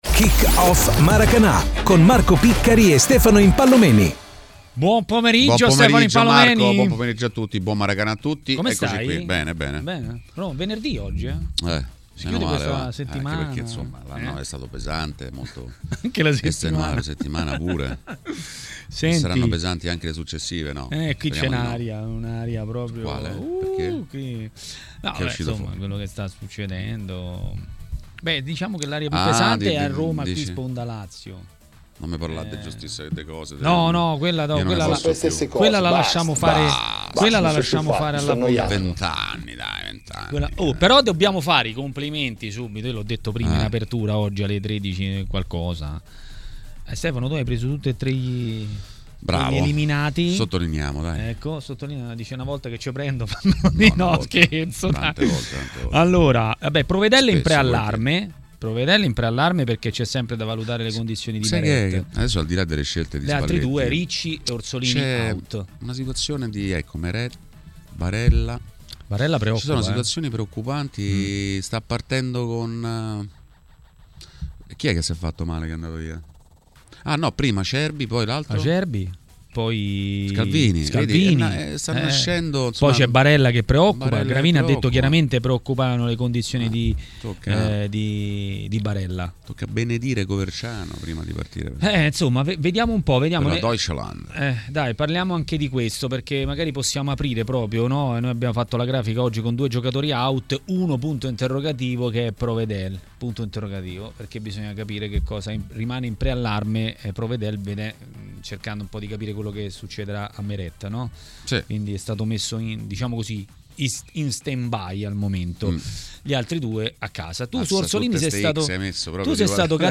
è intervenuto ai microfoni di TMW Radio nel corso di Maracanà.